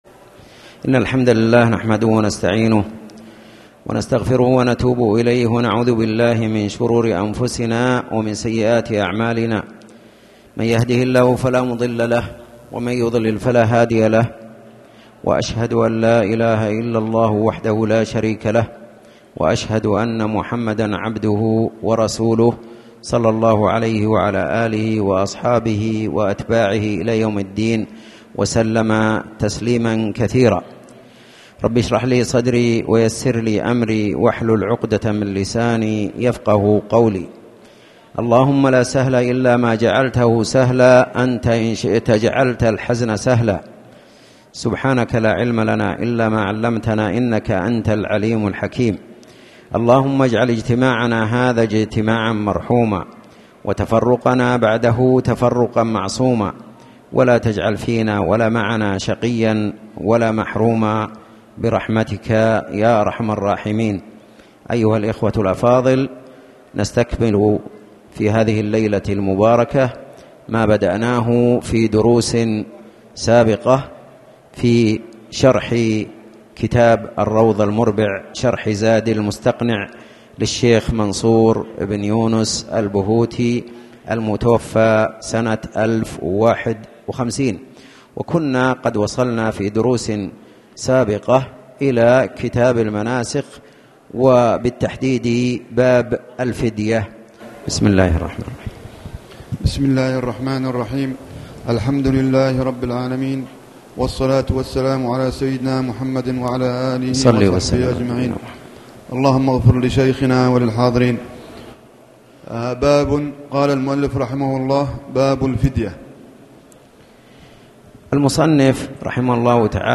تاريخ النشر ٥ محرم ١٤٣٩ هـ المكان: المسجد الحرام الشيخ